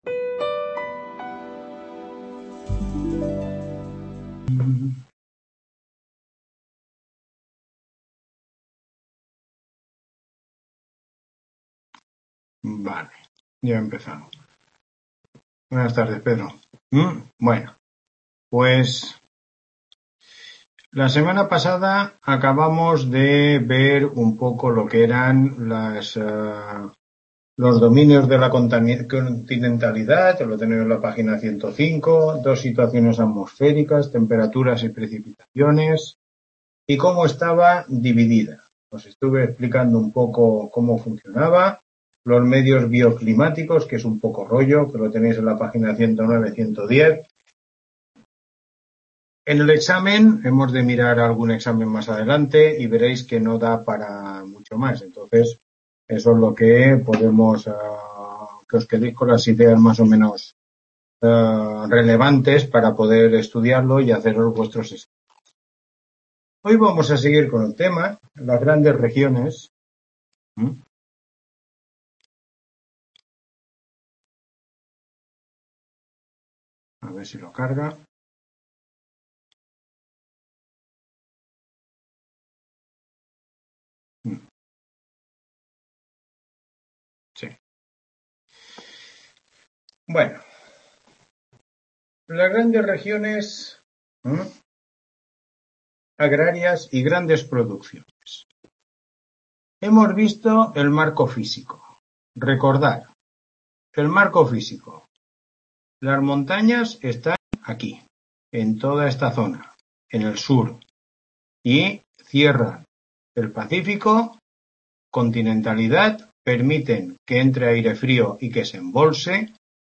Tutoría 4